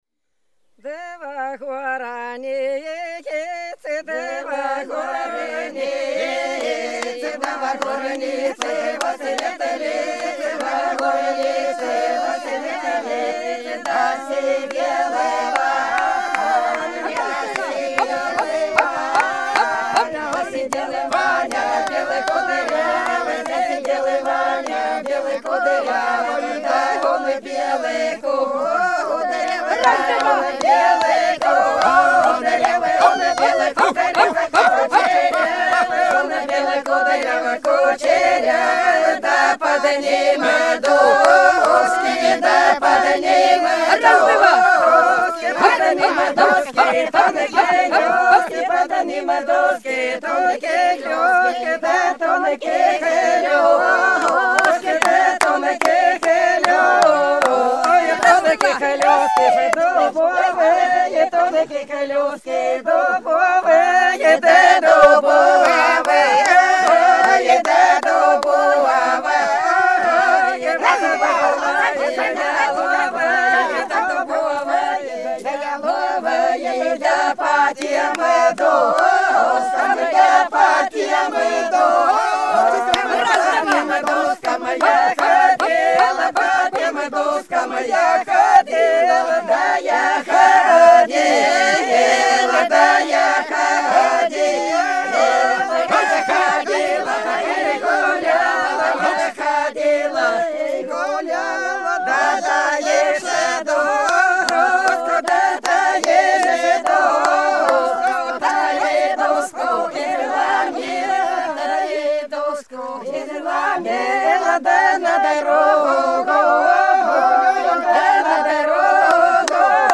Белгородские поля (Поют народные исполнители села Прудки Красногвардейского района Белгородской области) Во горнице, во светлице - плясовая